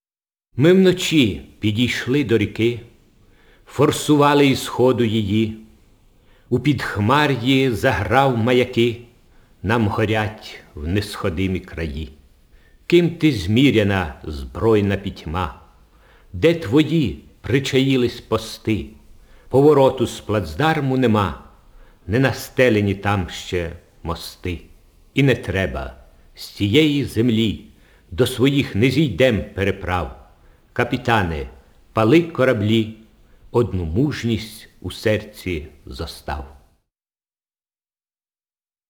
Index of /storage/Oles_Gonchar/Записи голосу Гончара